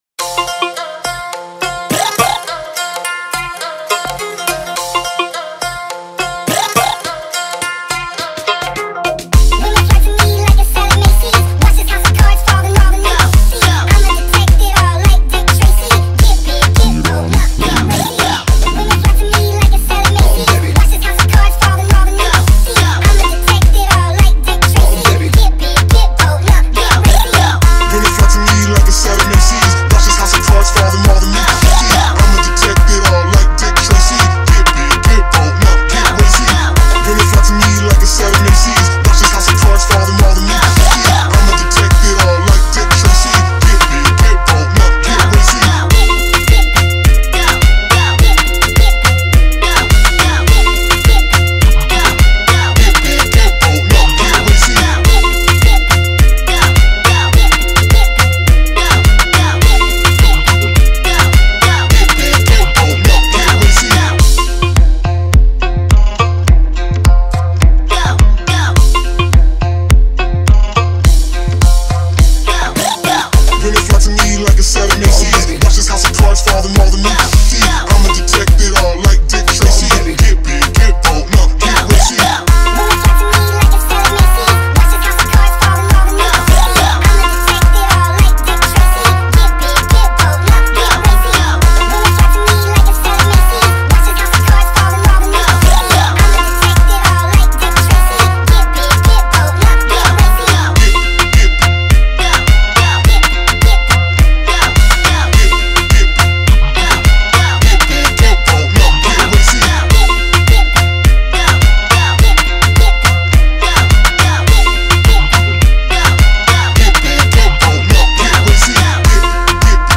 Download house remix for dance